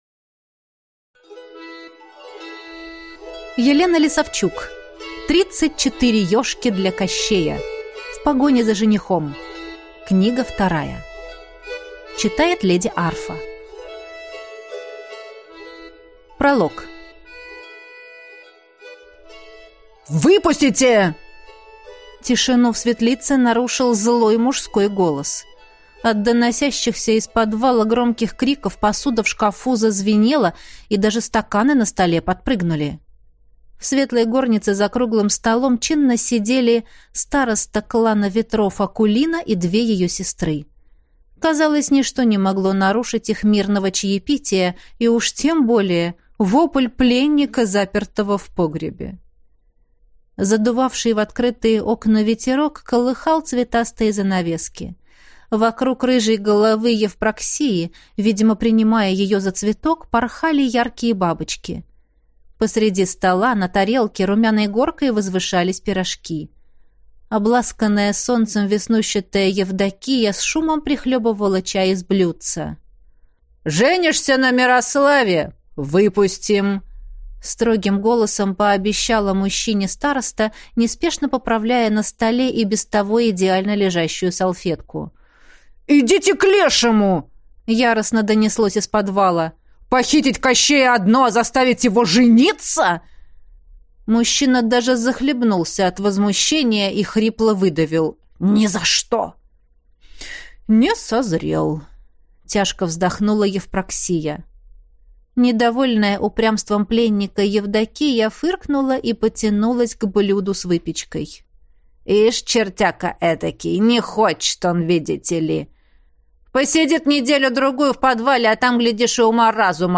Аудиокнига Тридцать четыре Ёжки для Кощея. В погоне за женихом.